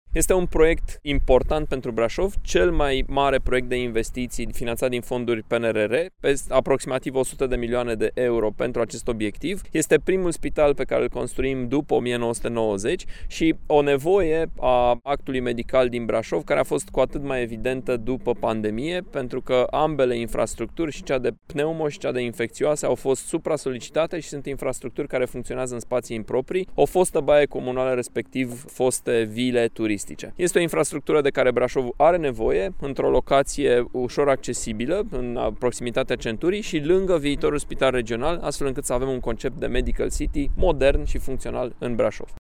Primarul municipiului Brașov, Allen Coliban: